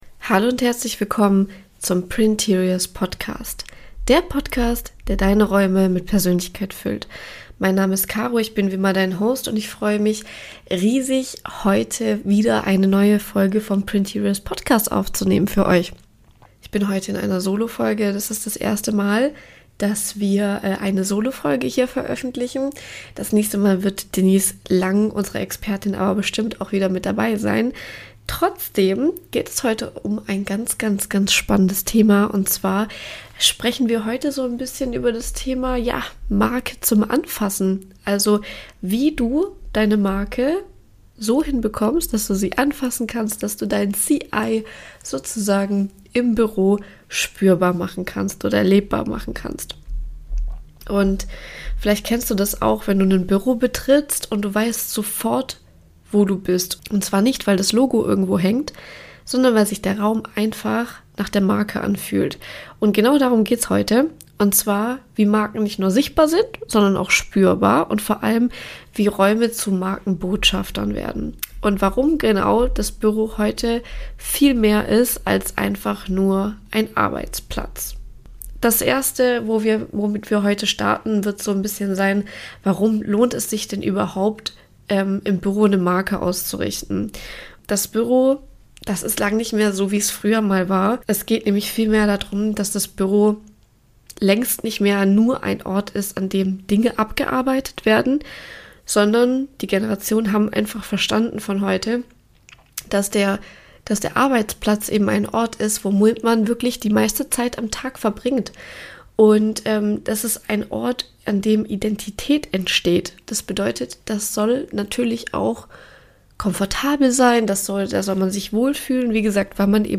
In Zeiten von Hybrid Work und Homeoffice kommen Menschen nicht wegen eines Schreibtischs zurück – sondern wegen Kultur, Zugehörigkeit und Identifikation. In dieser Solo-Episode des Printeriors Podcasts geht es um „Marke zum Anfassen“: Wie wird ein Office zum echten Brand Ambassador, sodass man sofort spürt, *wer ihr seid* – auch ohne Logos an jeder Wand?